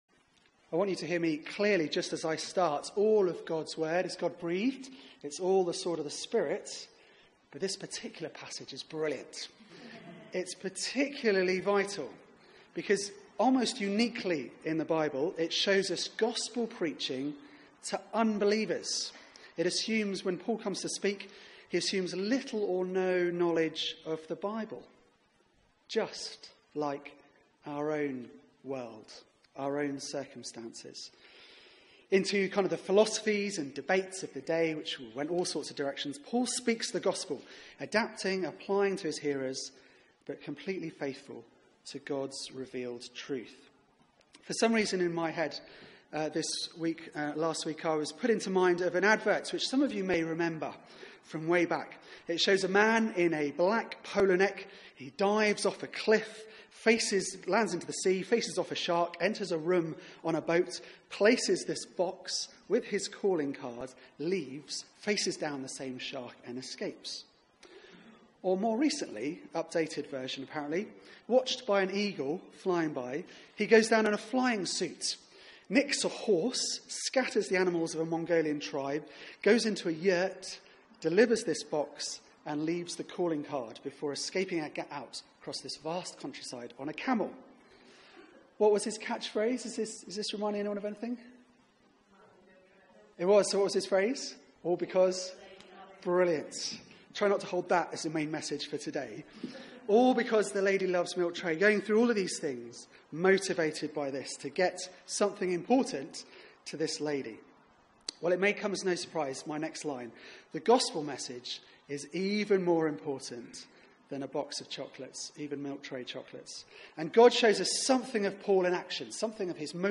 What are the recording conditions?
Media for 4pm Service on Sun 27th Aug 2017 16:00 Speaker